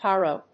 /ˈhɑro(米国英語), ˈhɑ:rəʊ(英国英語)/